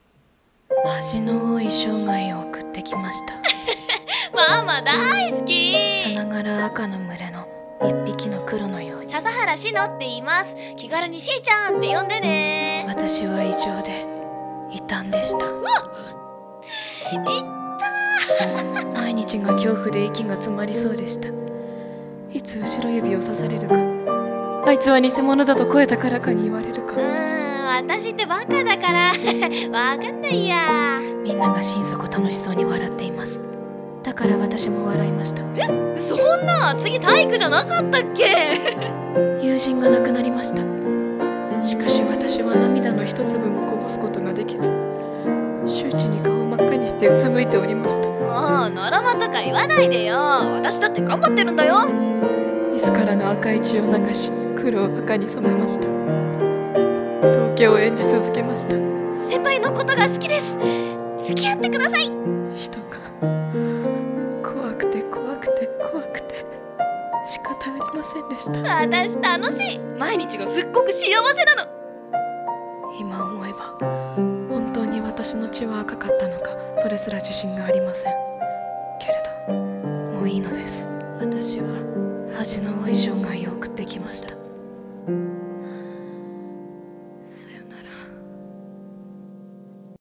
【一人声劇】失格